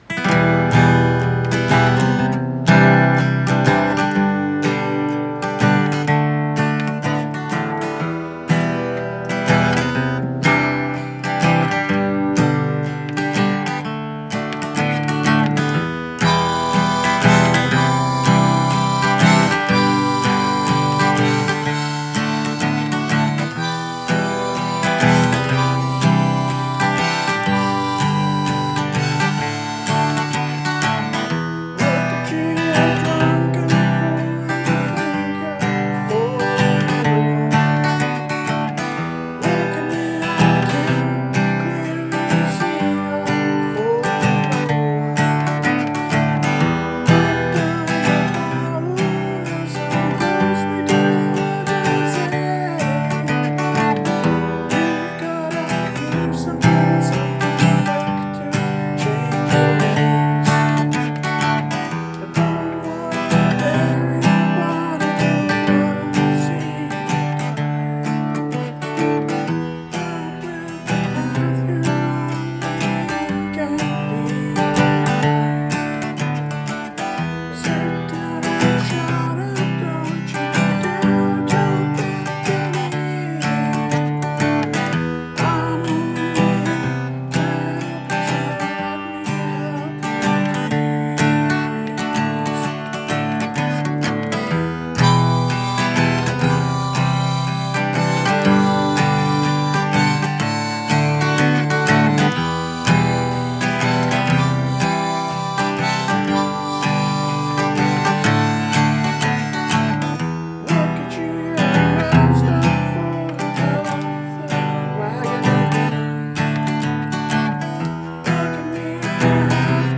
Much to everyone’s detriment I have acquired a set of harmonicas and a rack so I can play it as I play ...
Much to everyone’s detriment I have acquired a set of harmonicas and a rack so I can play it as I play guitar and sing. I’m not very good with it yet and this is about the best I could get out, so of course I had to play this song!
folkrock